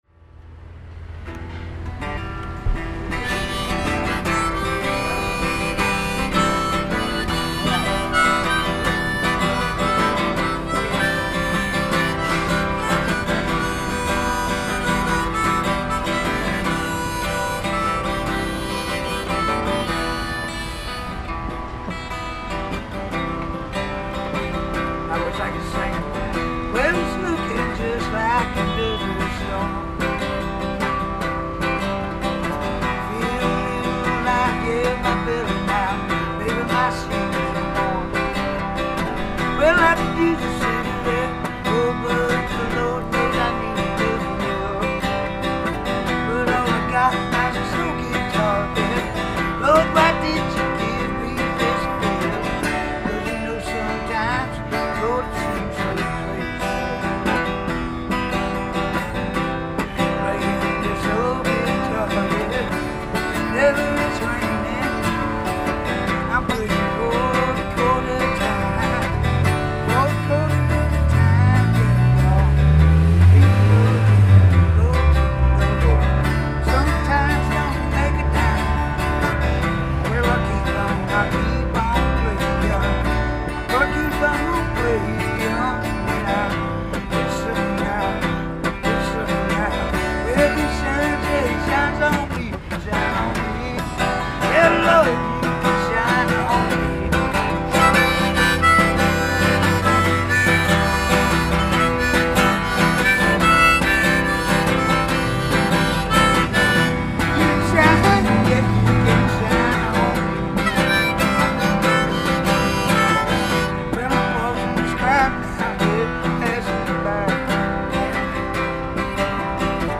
There were some problems with the video, and I didn’t realize until listening to the recordings much later that the actual audio wasn’t bad at all.